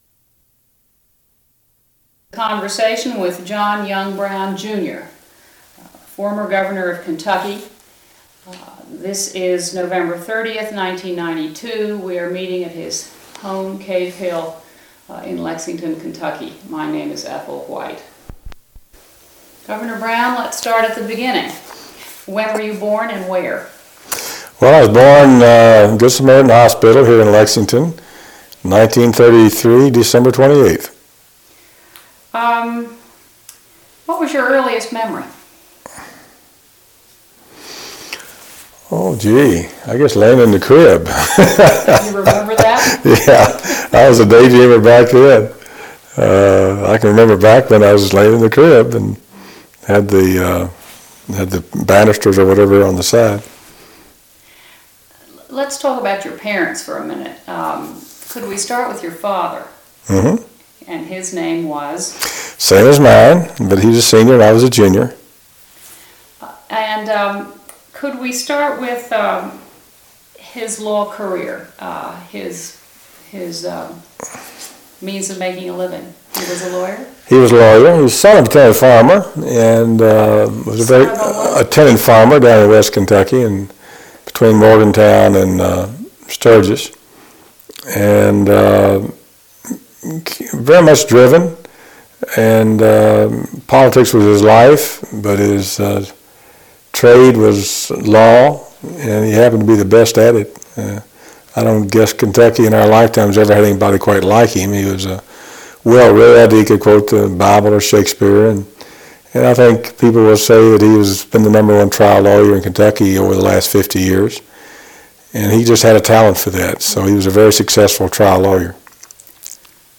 Oral History Interview with John Y. Brown, Jr., November 20, 1992